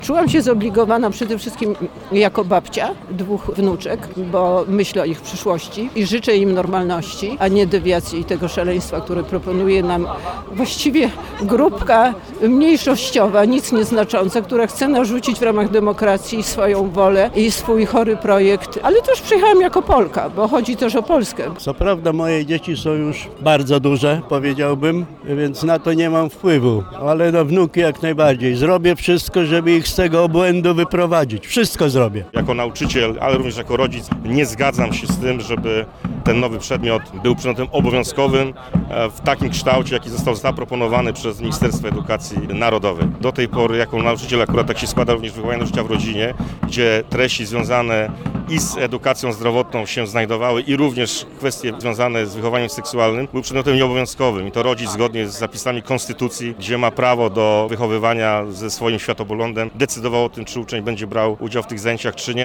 Dziś na Placu Zamkowym odbyła się manifestacja „Tak dla edukacji, nie dla deprawacji” , którą zorganizowała Fundacja Mamy i Taty.
Manifestujący nie kryli oburzenia zmianami, które chce wprowadzić Ministerstwo Edukacji Narodowej.
obrazekplaczamkowy.mp3